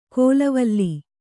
♪ kōlavalli